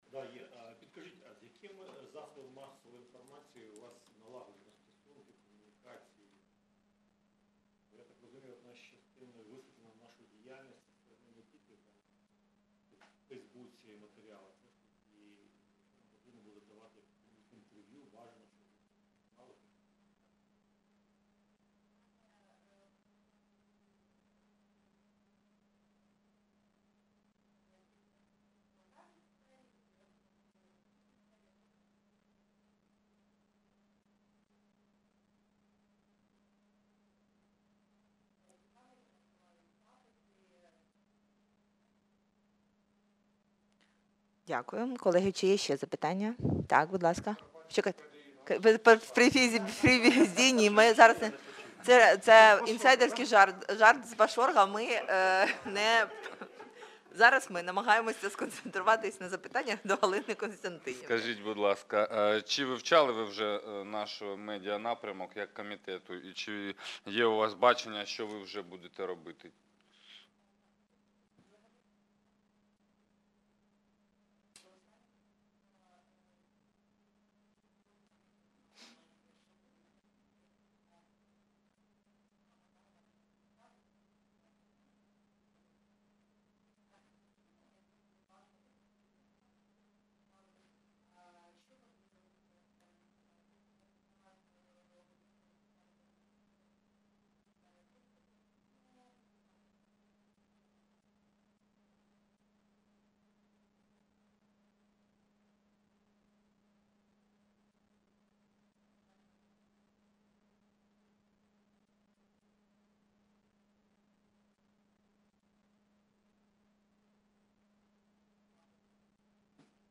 Аудіозапис засідання Комітету від 26.01.2022